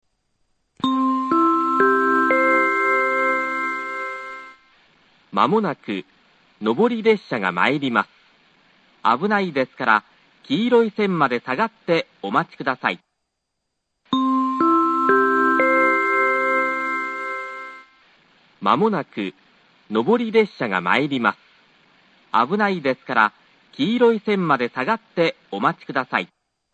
２番線接近放送 ３番線と同じです。
２回目の放送を言い始める頃には列車がホームに入ってきてしまいます。